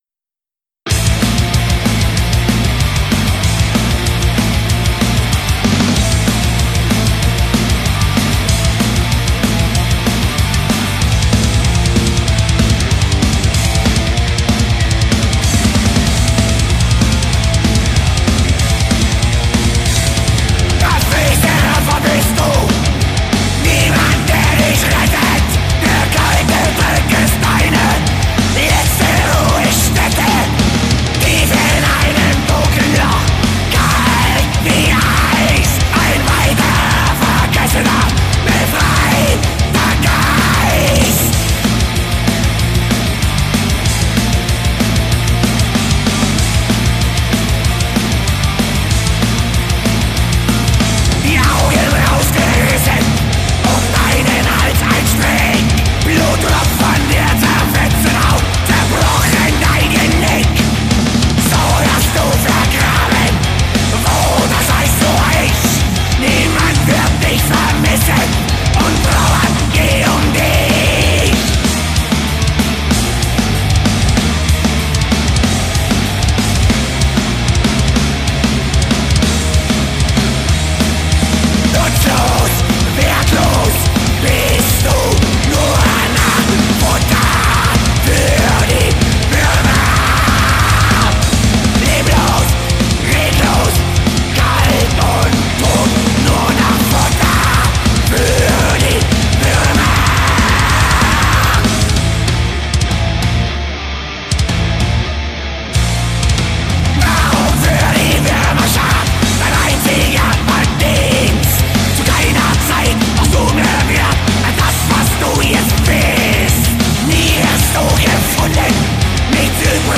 Black / Pagan